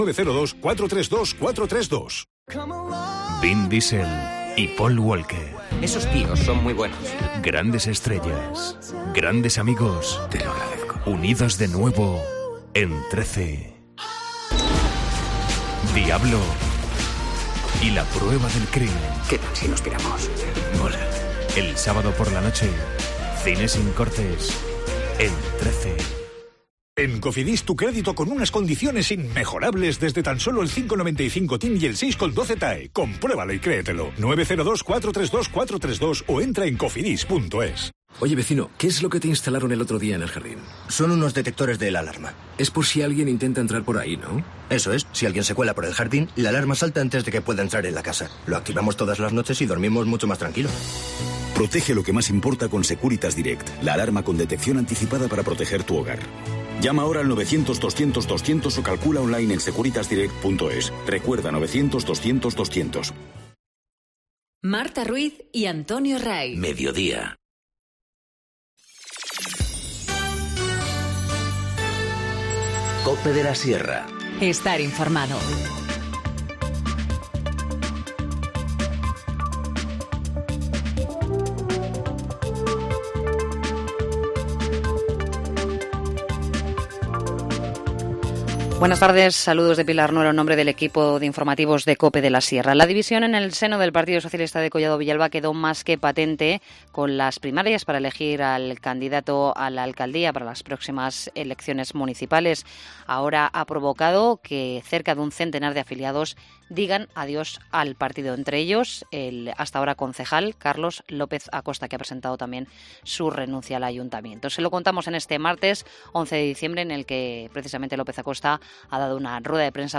Informativo Mediodía 11 dic- 14:20h